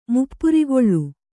♪ muppurigoḷḷu